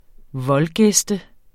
Udtale [ ˈvʌlˌ- ]